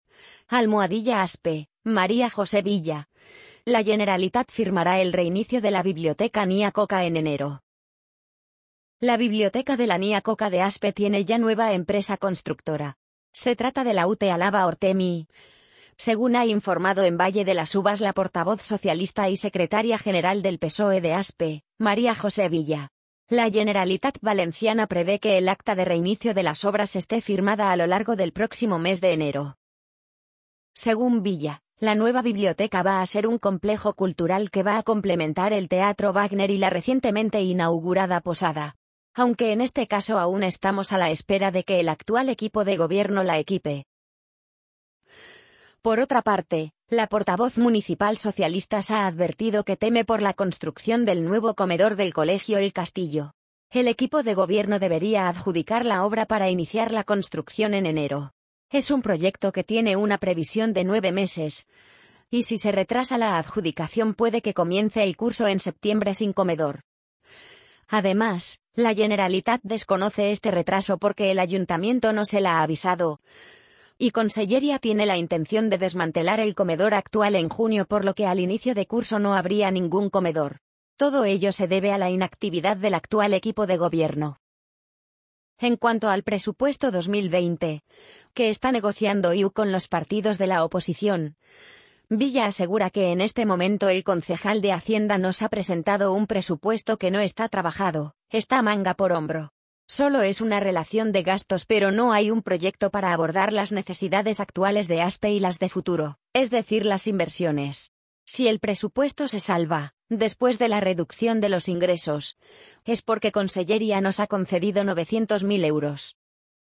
amazon_polly_39253.mp3